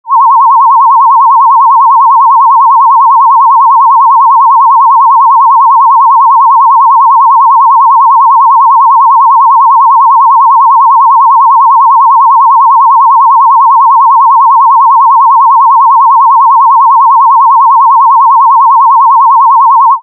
1/3 octave modulated sinus wave at 1 KHz and 0 dB 00' 20" modul017